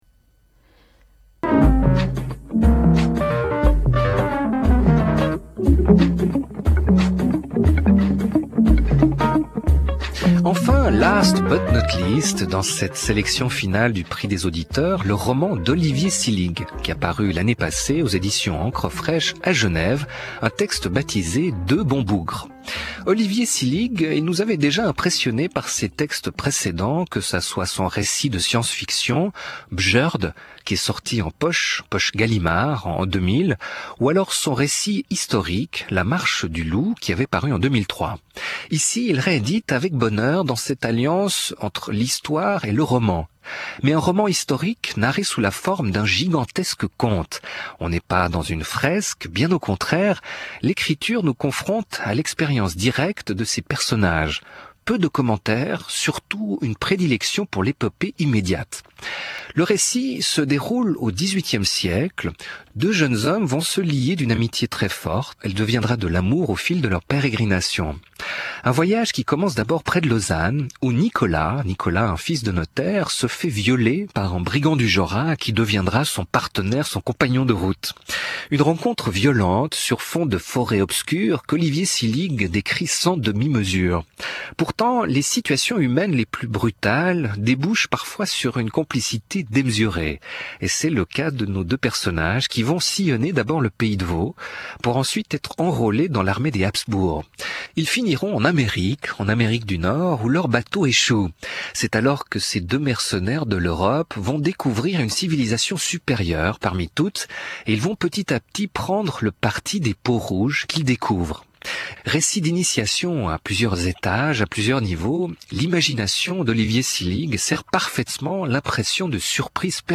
Le Prix des Auditeurs 2007 L'extrait de l'�mission concernant les Deux bons bougres